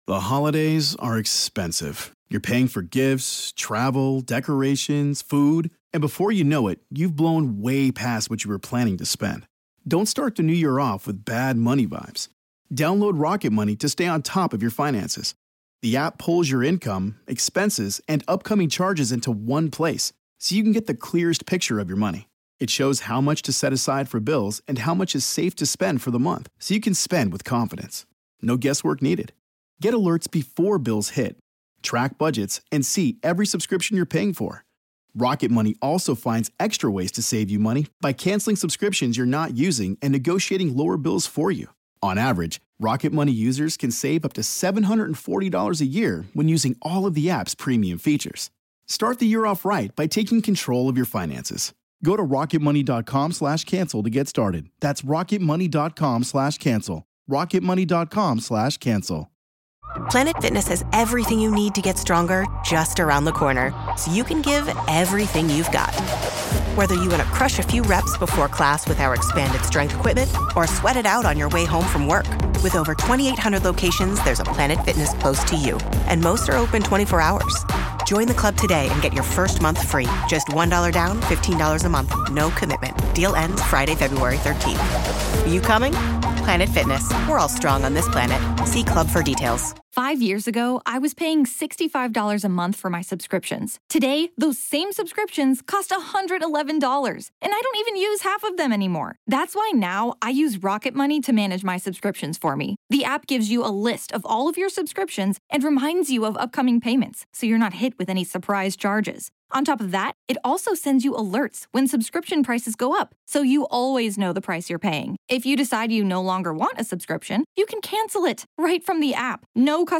If you’ve ever woken up shaken and wondered why a dream felt so real, this conversation may finally help you understand what your mind has been trying to say all along.